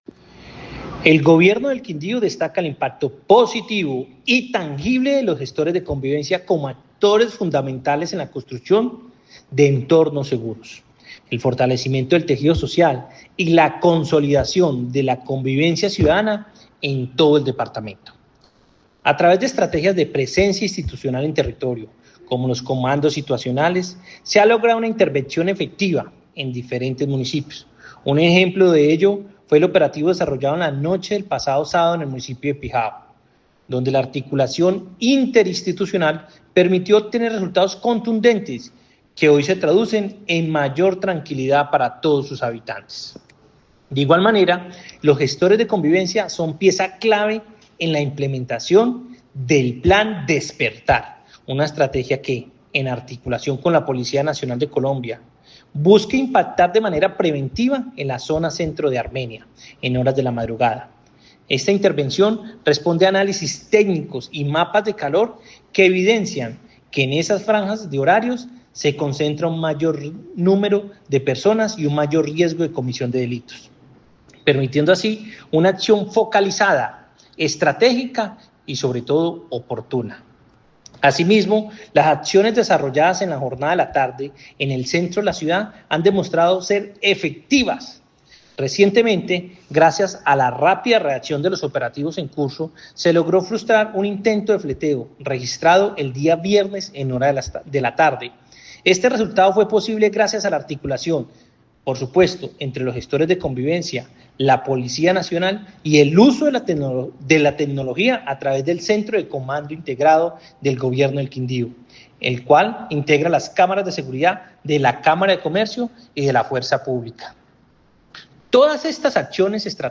Voz Jaime Andrés Pérez Cotrino – Secretario del Interior: